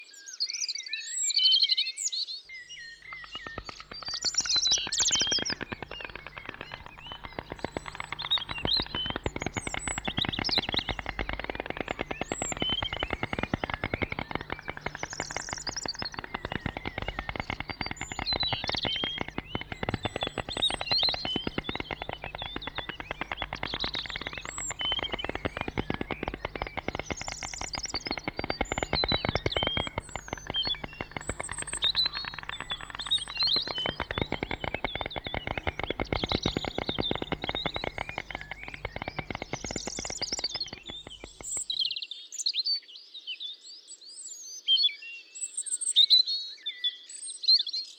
На этой странице собраны звуки аиста в разных ситуациях: крики, щелканье клювом, шум крыльев.
Белый аист в естественной среде